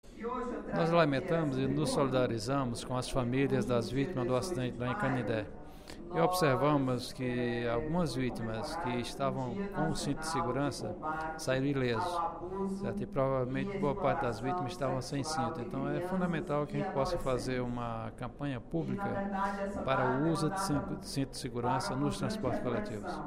O deputado Professor Pinheiro (PT) fez pronunciamento nesta terça-feira (20/05), durante o primeiro expediente da sessão plenária da Assembleia Legislativa, para abordar o acidente acontecido no último domingo, no município de Canindé.